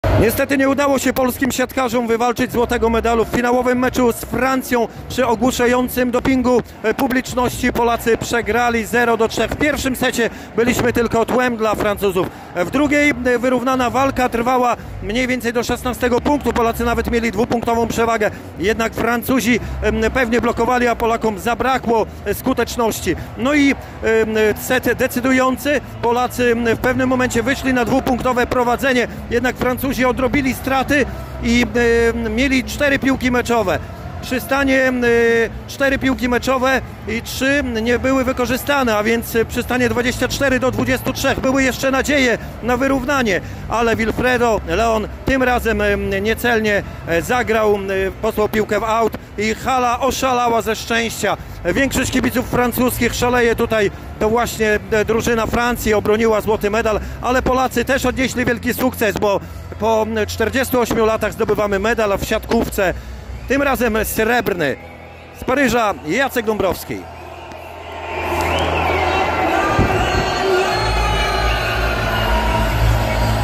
Porażka polskich siatkarzy w finale igrzysk - relacja